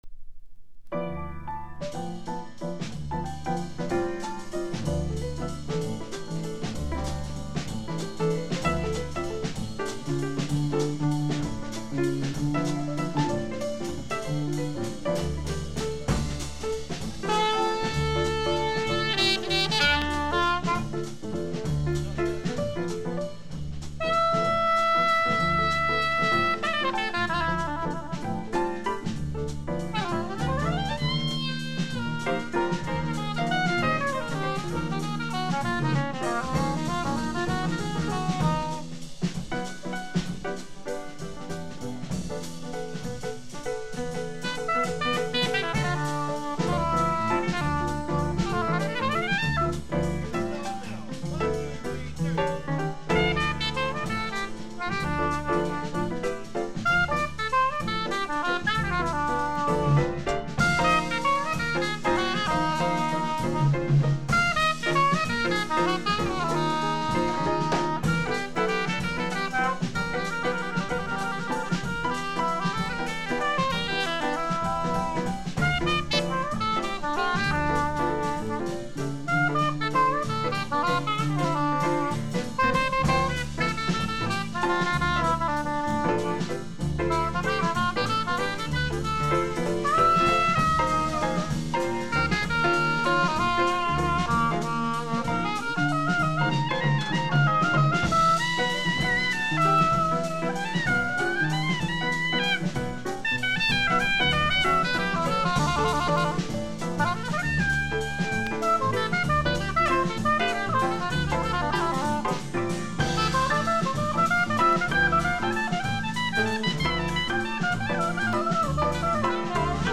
3曲ワンホーン。
（プレスにより少しチリ、プチ音ある曲あり）
Genre EURO JAZZ